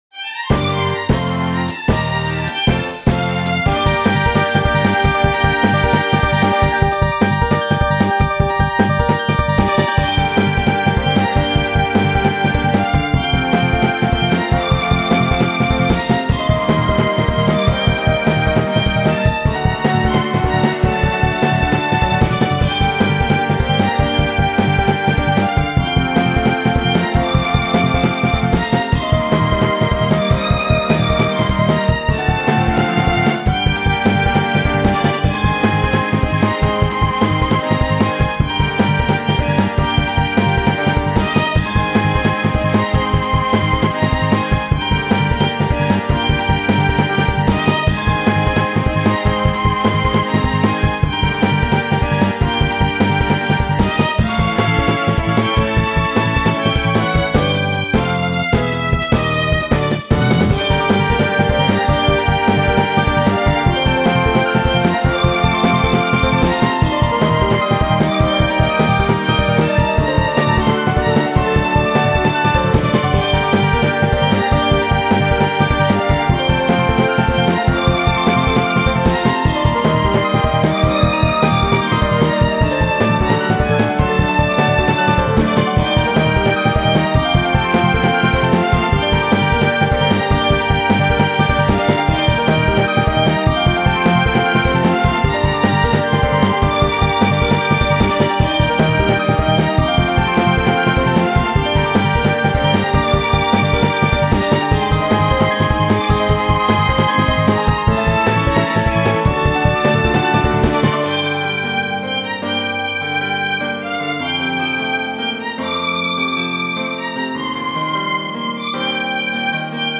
戦闘曲にはやっぱりピアノとバイオリンが似合いすぎる。